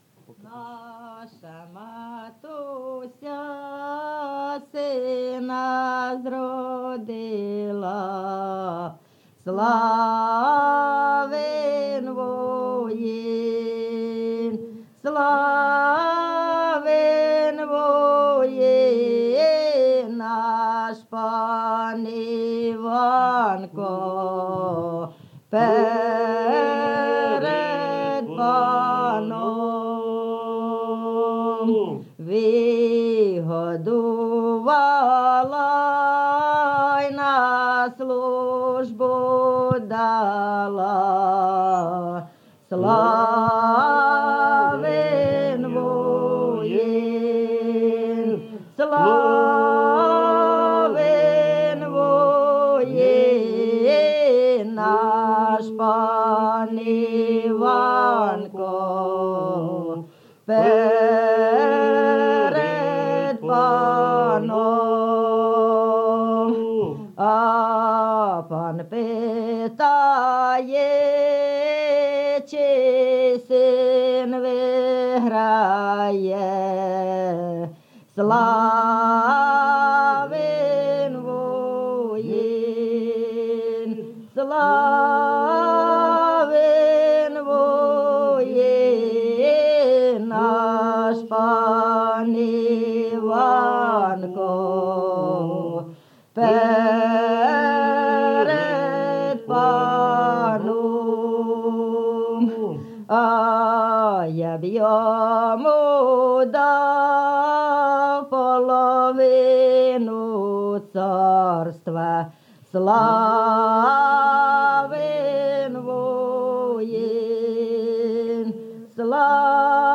ЖанрКолядки
Місце записус. Куземин, Охтирський район, Сумська обл., Україна, Слобожанщина